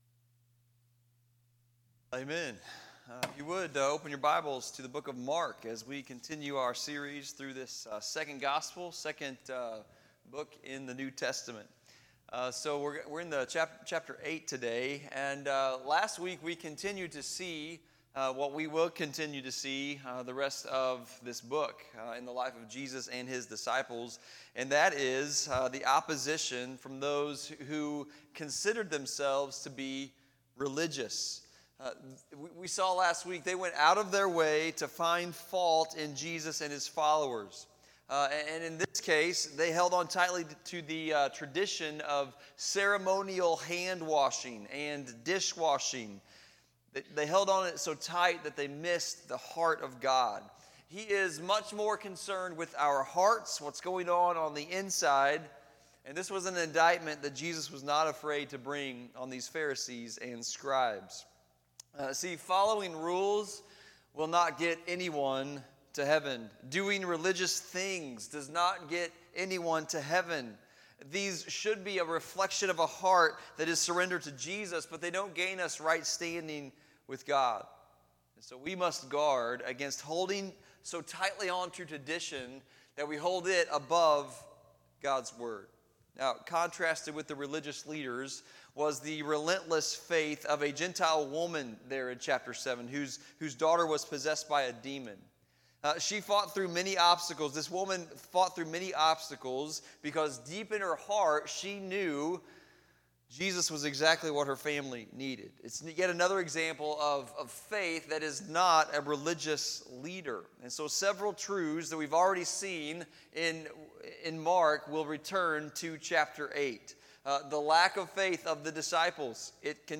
Sunday Service